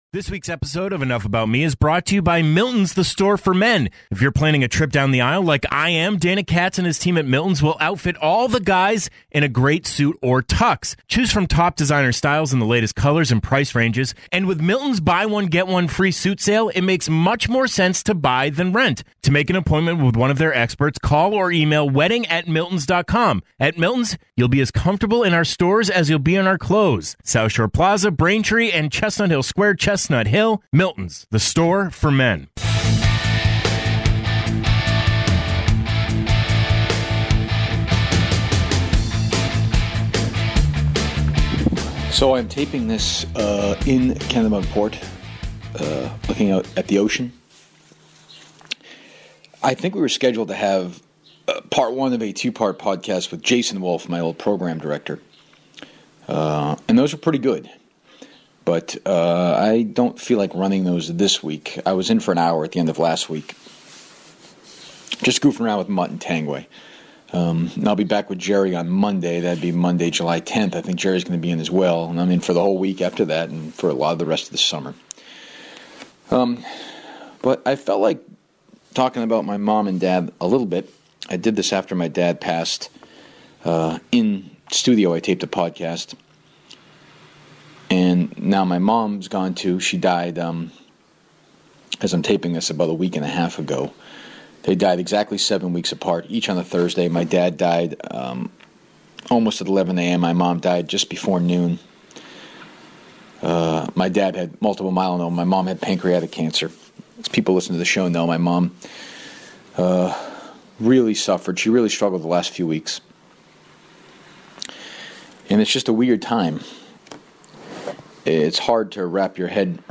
You will hear a man on the edge who is trying to work through the most difficult period of his life.